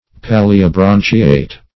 Search Result for " palliobranchiate" : The Collaborative International Dictionary of English v.0.48: Palliobranchiate \Pal`li*o*bran"chi*ate\, a. [See Pallium , and Branchia .]
palliobranchiate.mp3